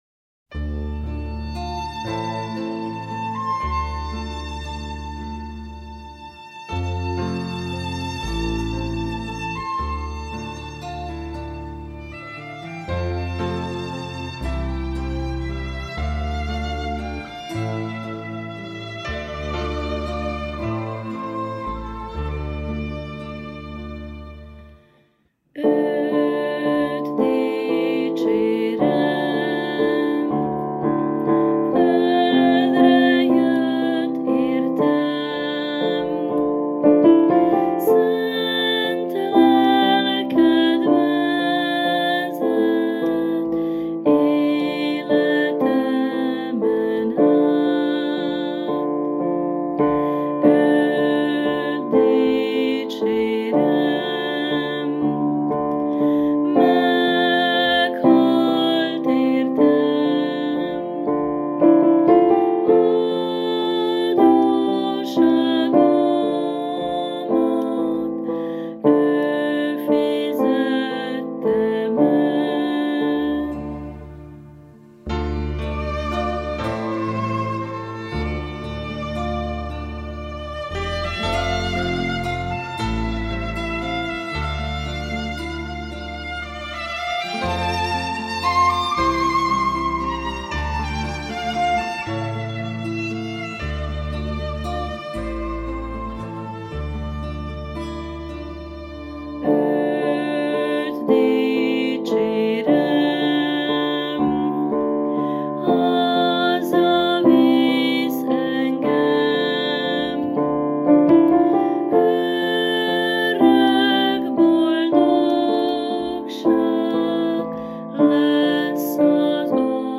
finn keresztény himnusz